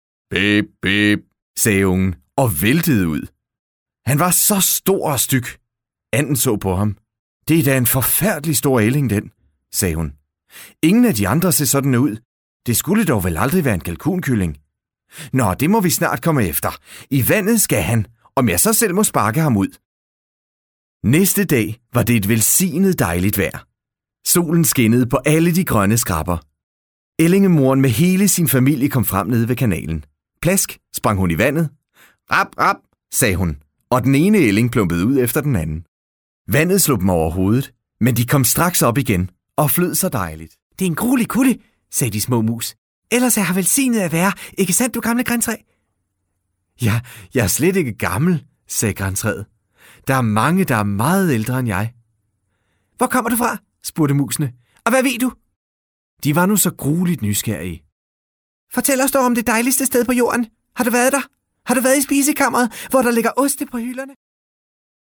Professional danish voiceover artist with a very versatile voice. Voice characters from warm & trustworthy to upbeat / crazy.
Sprechprobe: Industrie (Muttersprache):